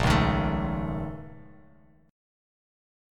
A#mM11 chord